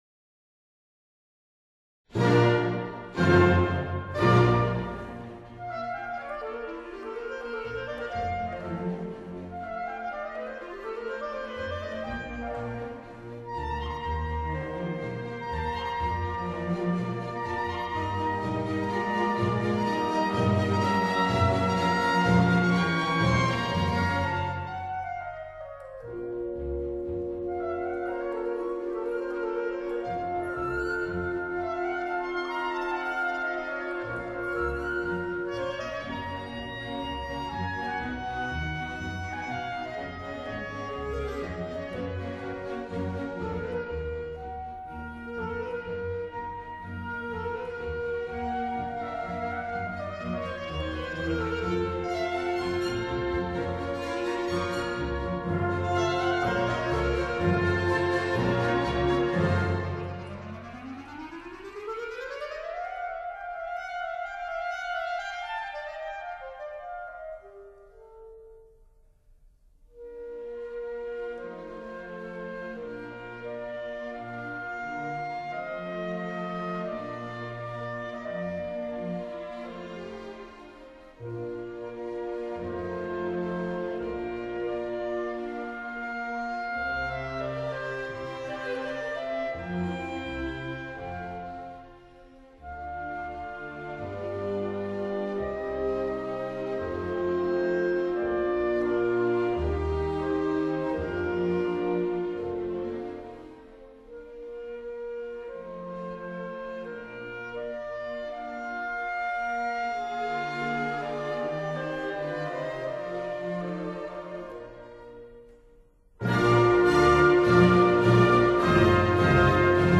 for clarinet & orchestra